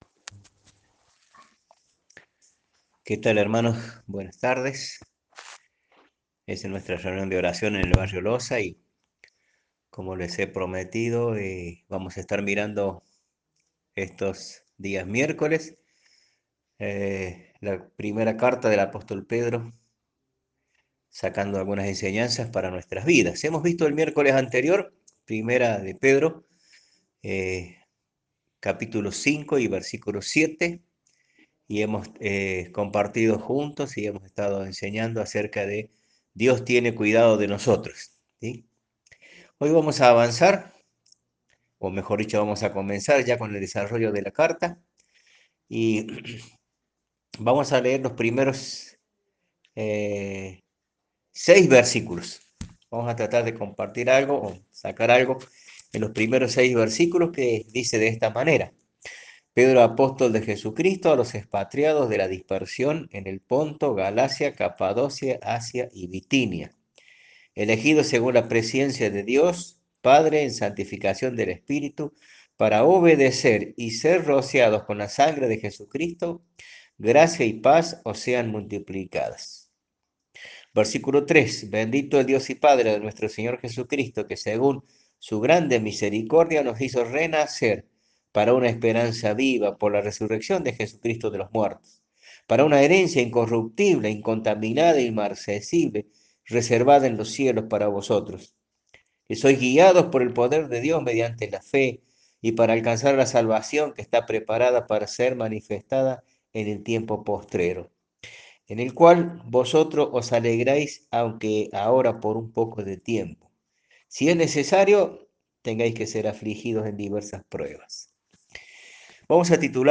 Audio 2 de estudio de 1º PEDRO - La Revista de Rincón